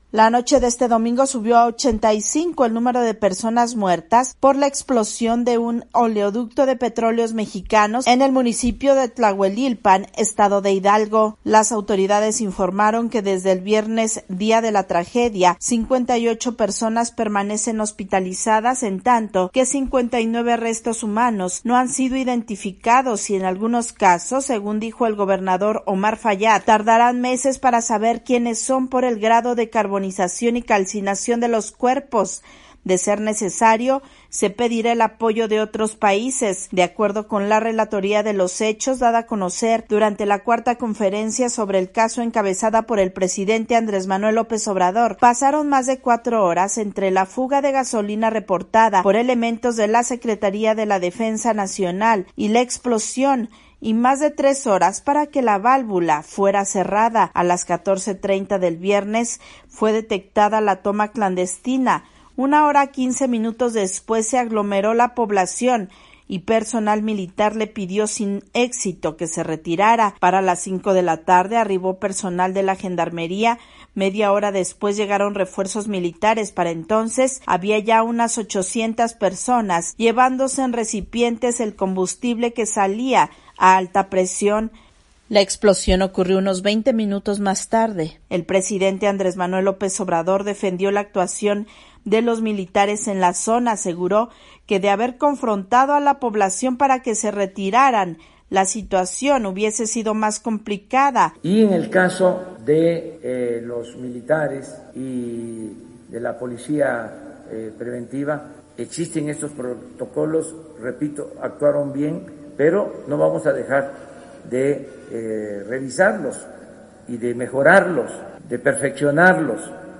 VOA: INFORME DE MÉXICO